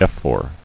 [eph·or]